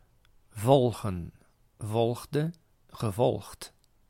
Ääntäminen
IPA: /ˈvɔl.ɣǝ(n)/